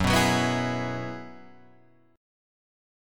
F#9sus4 chord